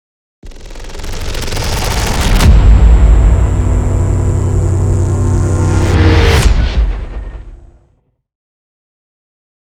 Download Logo Reveal sound effect for free.
Logo Reveal